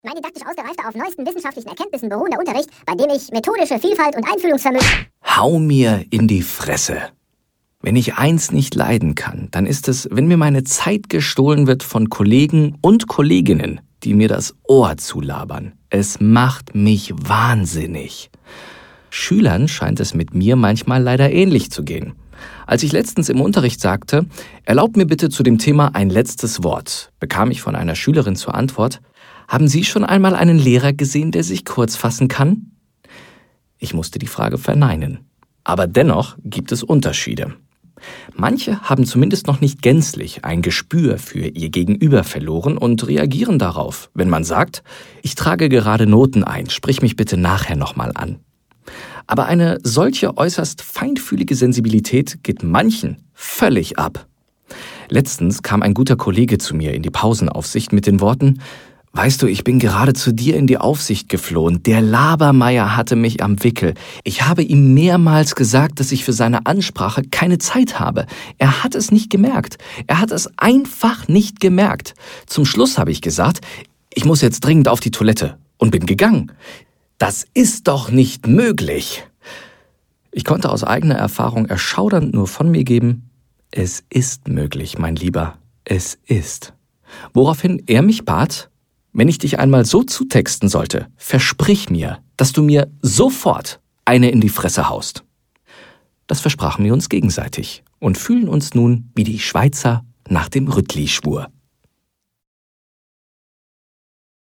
Sehr vielseitige und warme Stimme.
Kein Dialekt
Sprechprobe: Sonstiges (Muttersprache):
german voice over artist